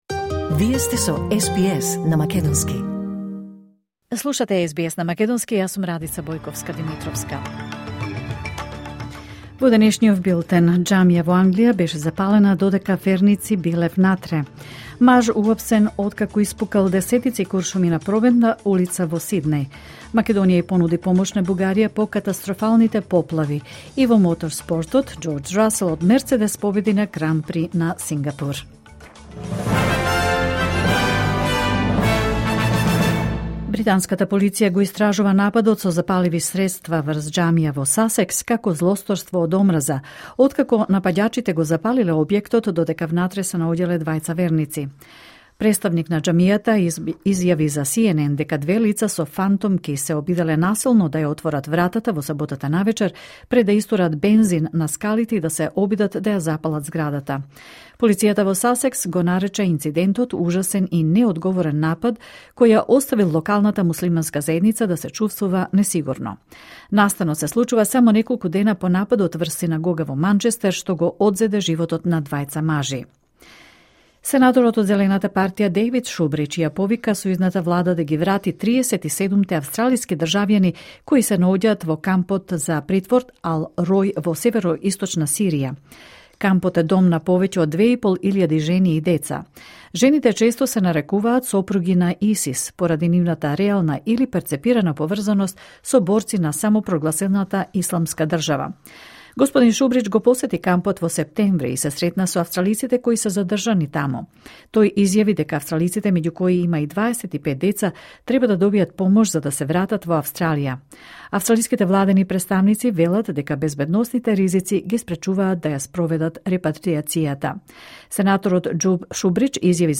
Вести на СБС на македонски 6 октомври 2025